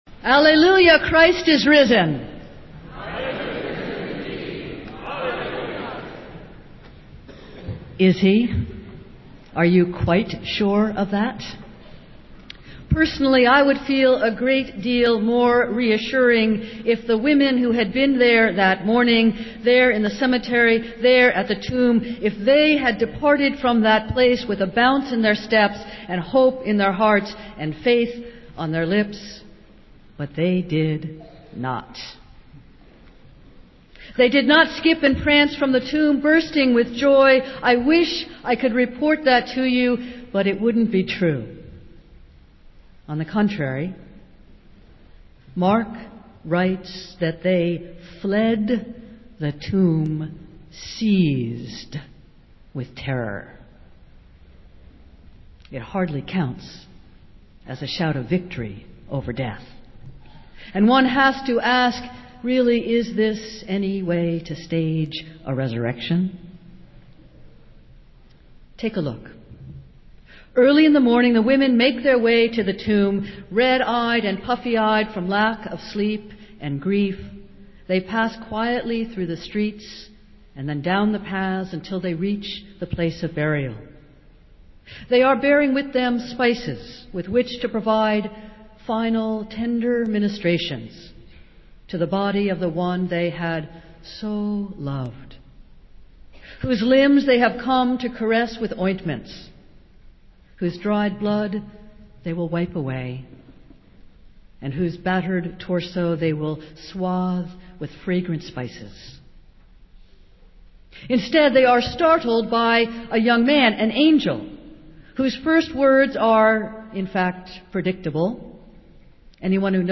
Festival Worship - Easter Sunday